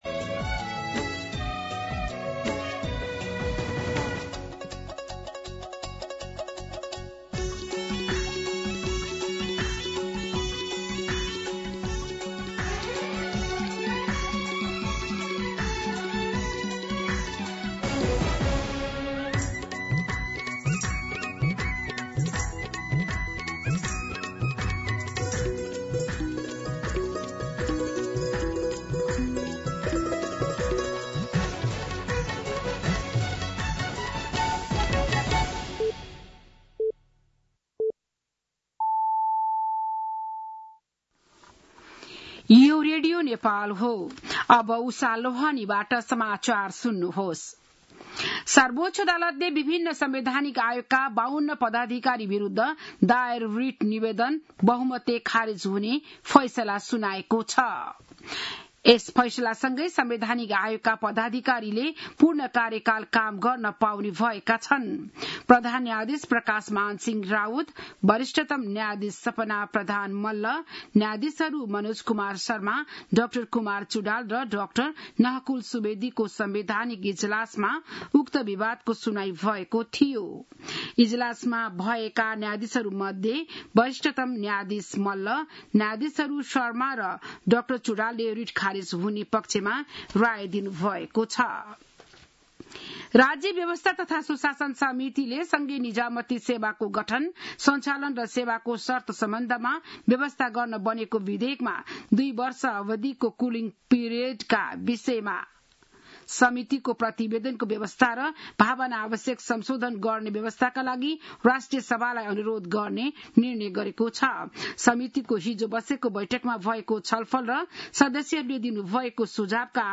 बिहान ११ बजेको नेपाली समाचार : १९ असार , २०८२